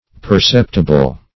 Perceptible \Per*cep"ti*ble\ (p[~e]r*s[e^]p"t[i^]*b'l), a. [L.